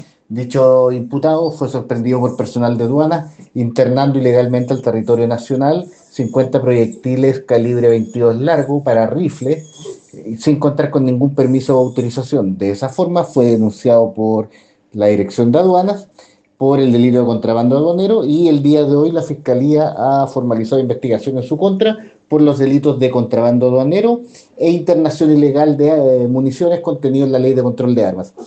Al respecto, el fiscal Miguel Ángel Velásquez afirmó a La Radio que el trasandino “fue sorprendido por personal de aduana internando ilegalmente al territorio nacional 50 proyectiles calibre 22, largo para rifle y sin contar con ningún permiso o autorización“.
fiscal-exdiputado-argentino.mp3